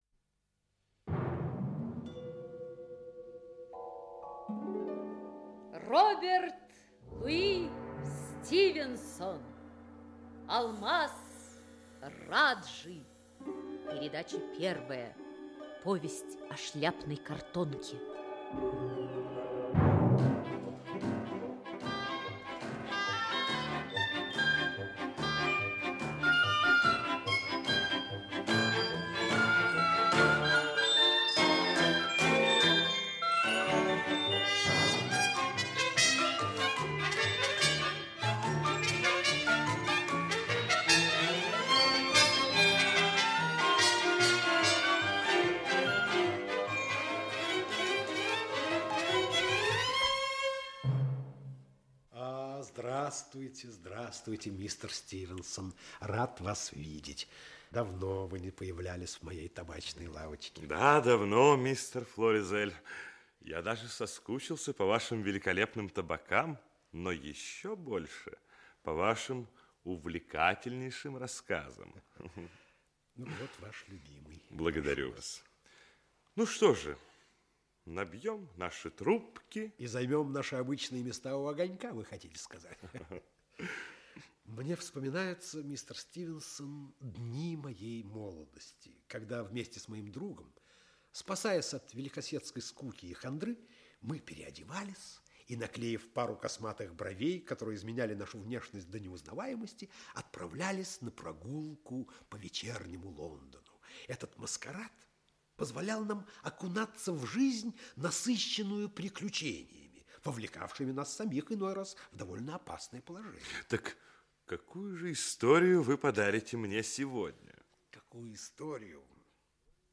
Аудиокнига Алмаз Раджи (спектакль) | Библиотека аудиокниг
Aудиокнига Алмаз Раджи (спектакль) Автор Роберт Льюис Стивенсон Читает аудиокнигу Олег Табаков.